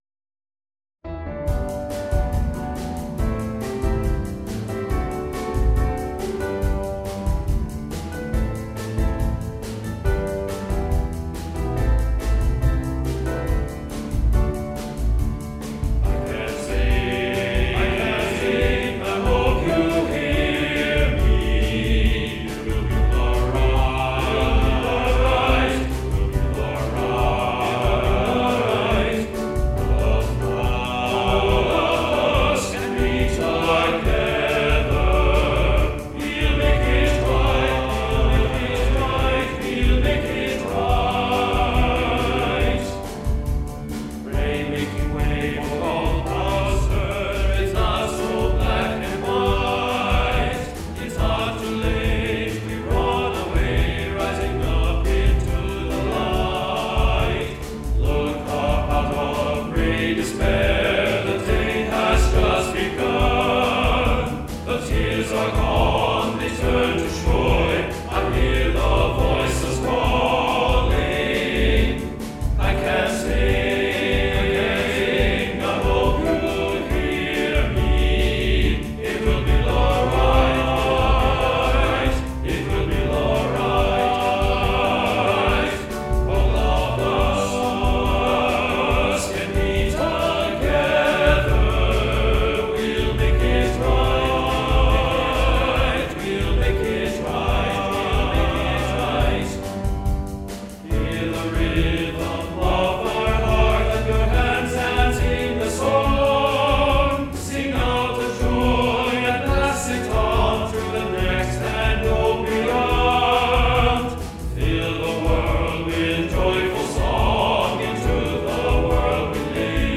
This song was written for a choir in the UK.